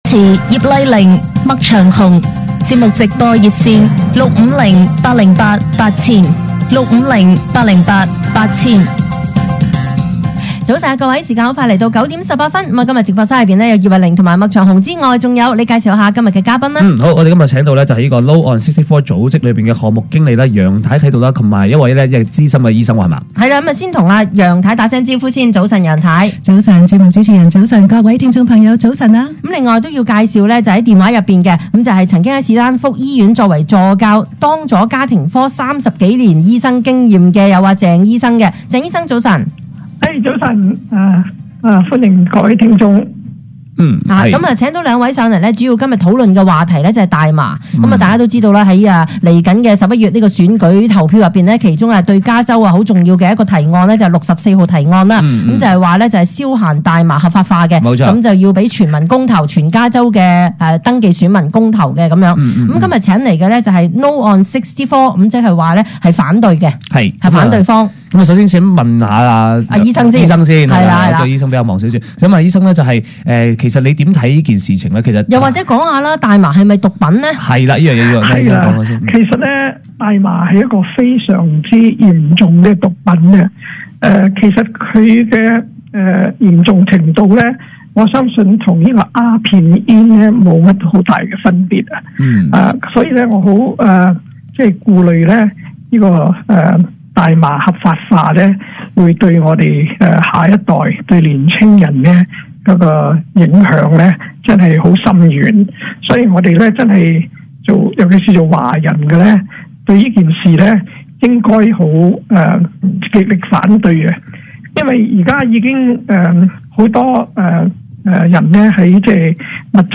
加州今年十一月將為64號提案（大麻合法化）公投，我建議大家要投反對票。 (….) 星島中文有關64號大麻提案訪問錄音（無廣告）